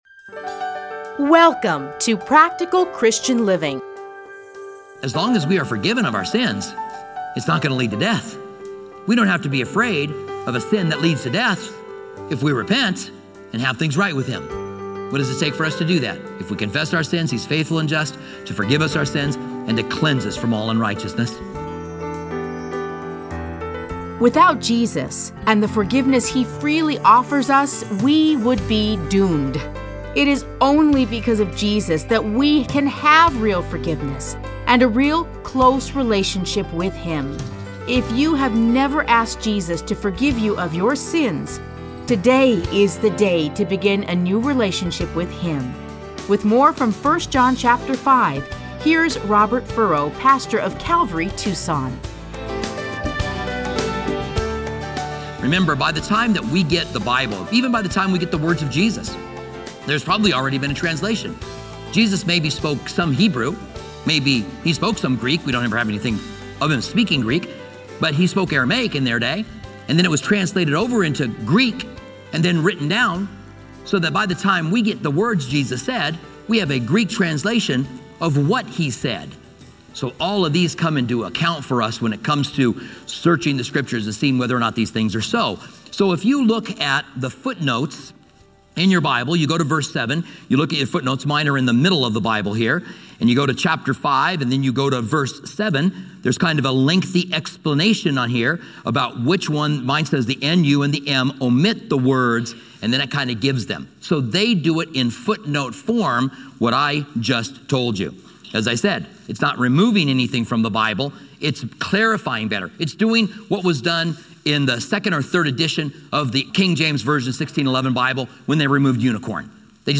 Listen to a teaching from 1 John 5:6-12.